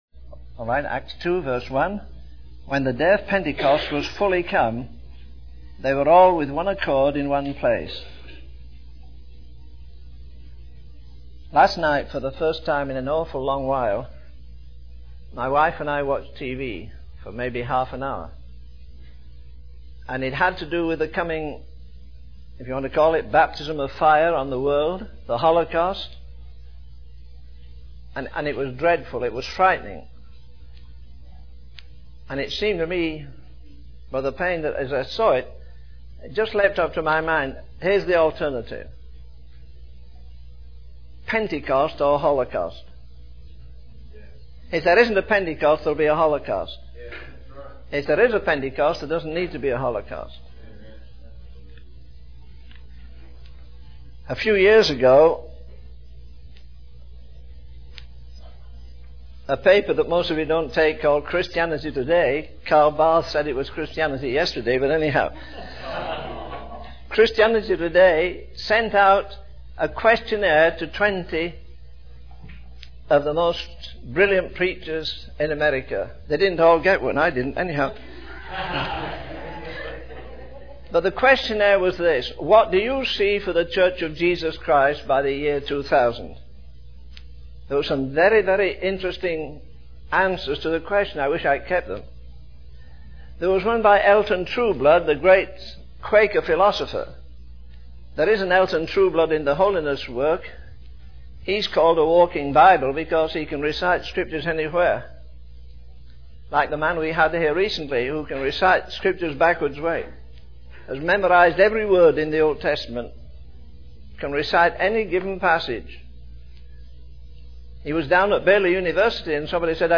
In this sermon, the speaker discusses the significance of God leading the children of Israel through the wilderness with a pillar of fire by night and a pillar of cloud by day. He emphasizes that our God is a consuming fire, highlighting the power and presence of God. The speaker then shifts to discussing Peter's preaching, noting that it was powerful, pointed, and painful.